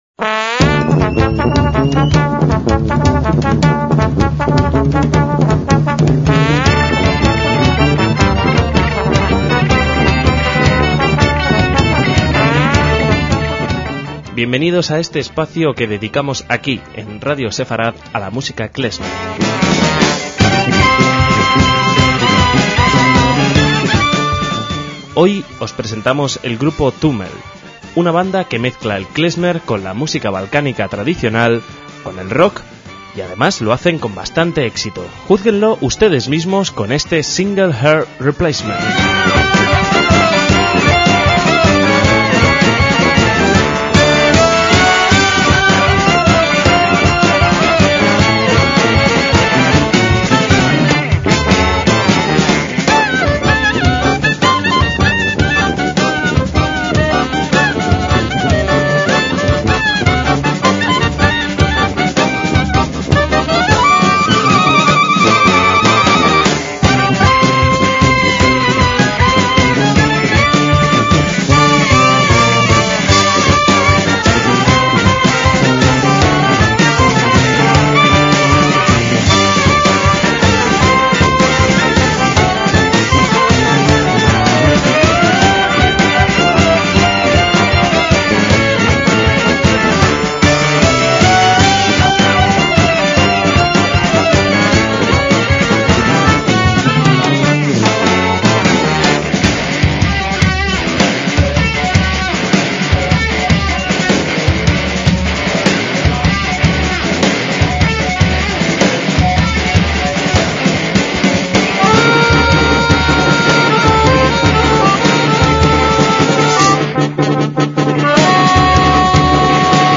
MÚSICA KLEZMER
clarinete y canto
percusión
saxo
guitarras
violín
acordeón